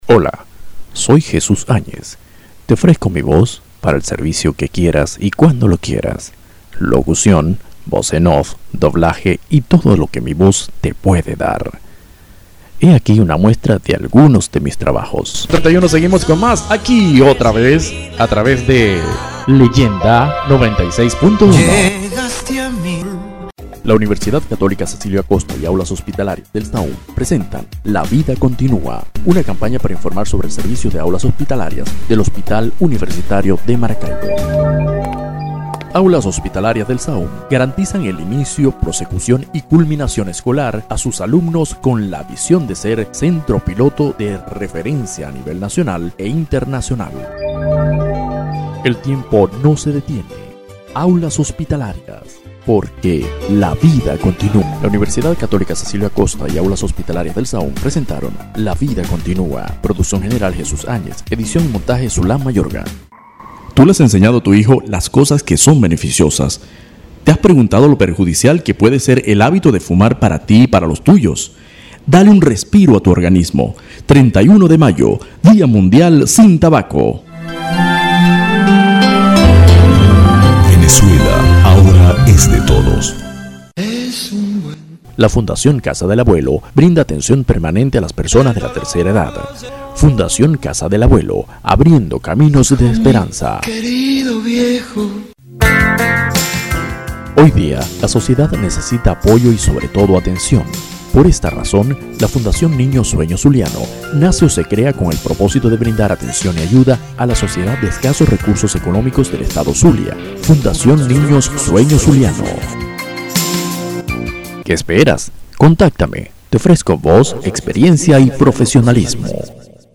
Kein Dialekt